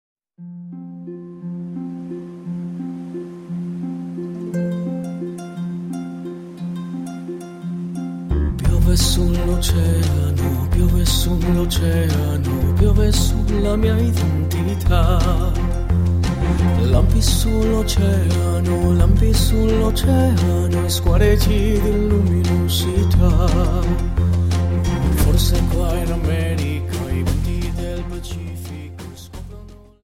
Dance: Viennese Waltz Song